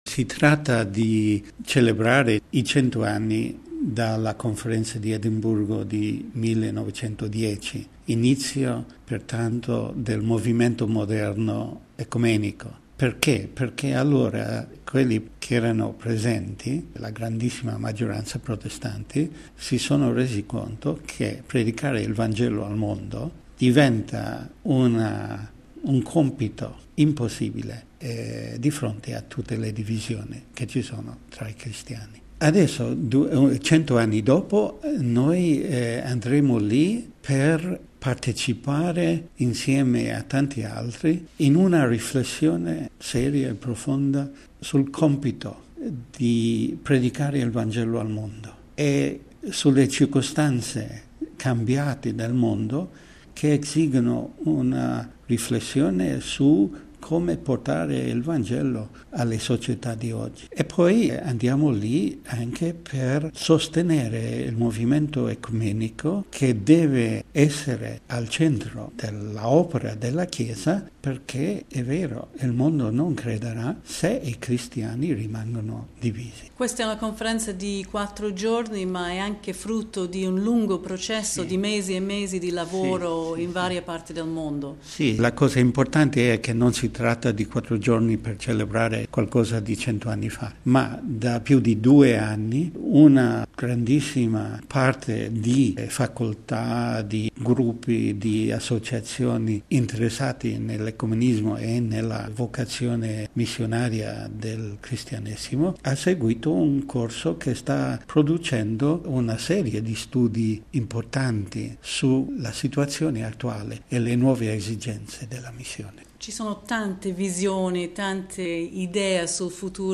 A guidare la delegazione cattolica alla Conferenza di Edimburgo è il vescovo Brian Farrell, segretario del Pontificio Consiglio per l'Unità dei Cristiani.